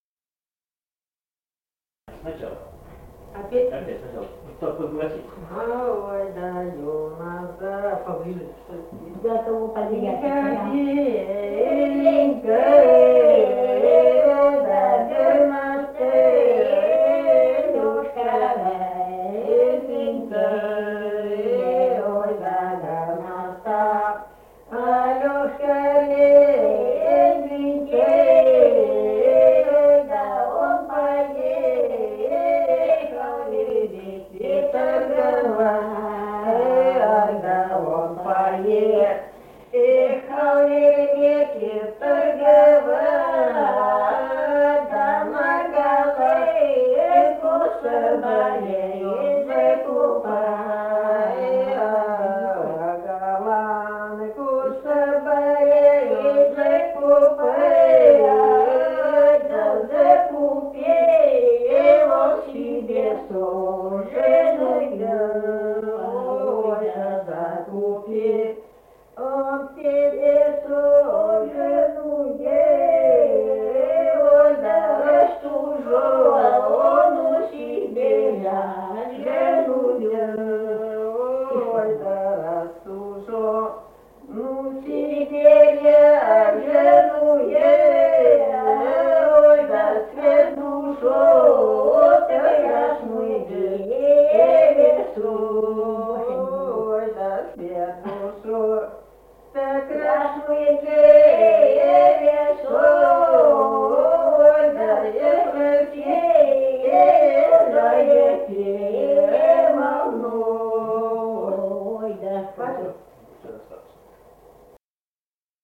Файл:11б Ой, да у нас заинька беленький (двухголосие) И1772-06б Белое.wav.mp3 — Фолк депозитарий
Республика Казахстан, Восточно-Казахстанская обл., Катон-Карагайский р-н, с. Белое, июль 1978.